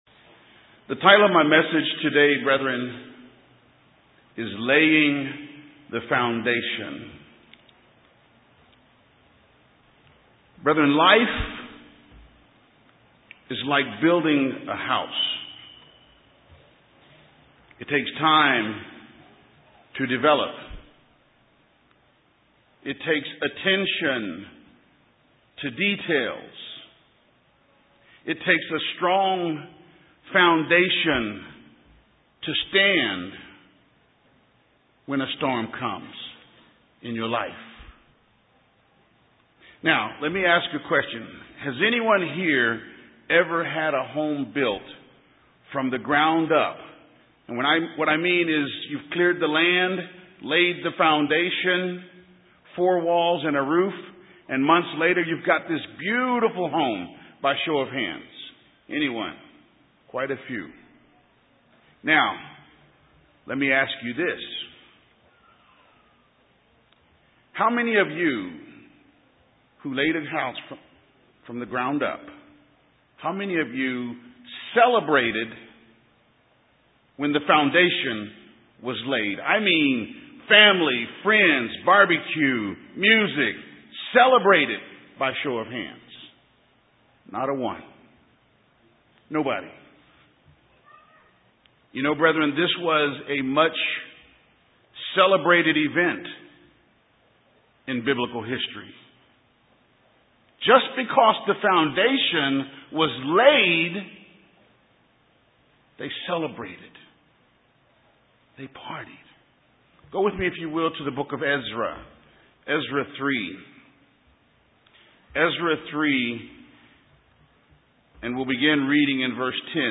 Given in San Antonio, TX Austin, TX